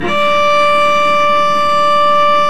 Index of /90_sSampleCDs/Roland L-CD702/VOL-1/STR_Vc Marc&Harm/STR_Vc Harmonics